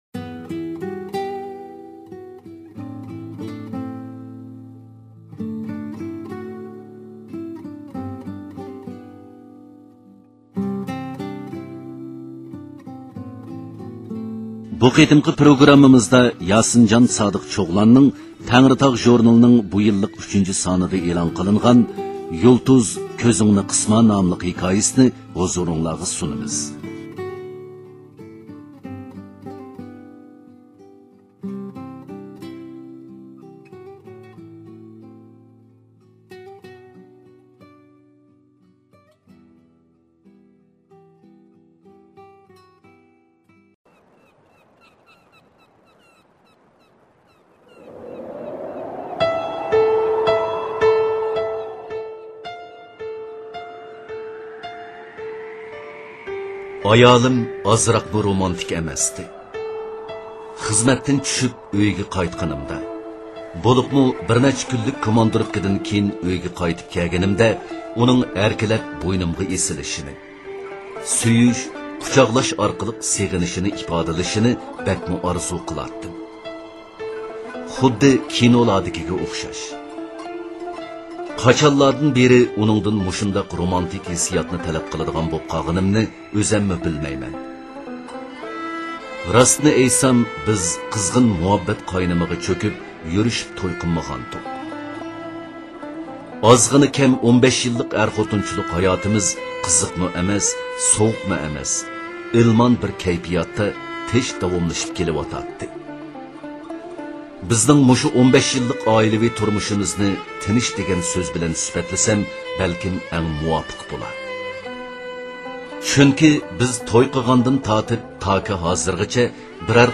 يۇلتۇز كۆزۈڭنى قىسما (ئاۋازلىق)
mp3/yultuzkozungni.mp3 مەزكۇر ئەسەر ئىلگىرى مەركىزى خەلق رادىئو ئىستانىسىنىڭ ئەدەبىي ئاڭلىتىشىدا ئاڭلىتىلغان بولۇپ ، قايتا مۇزىكا ئىشلىنىپ تورداشلارنىڭ كۆڭۈل ئارامىغا سۇنۇلدى .